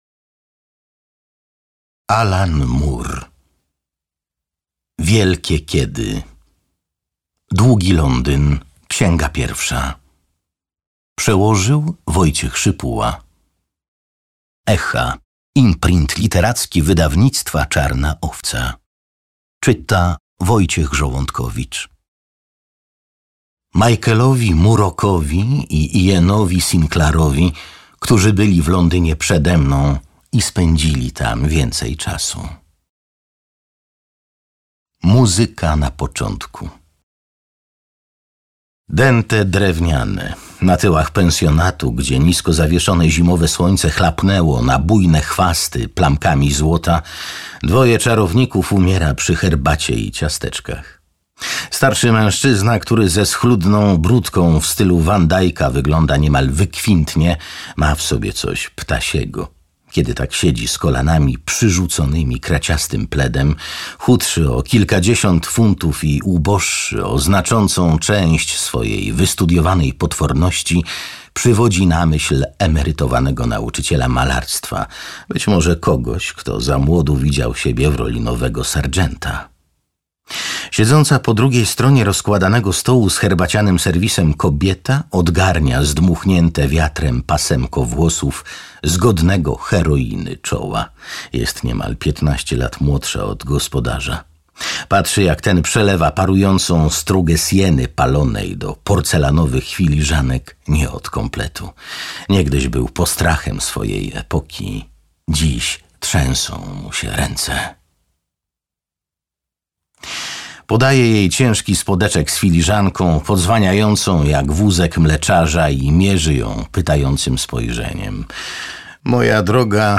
Długi Londyn. Wielkie Kiedy. Długi Londyn. Księga pierwsza - Alan Moore, Alan Moore - audiobook